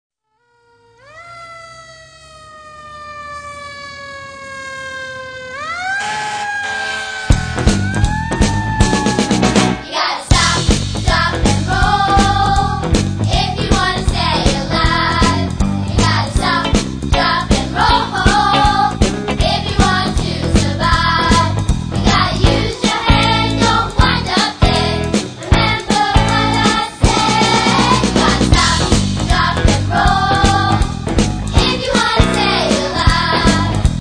A rock „n‟ roll fire safety song!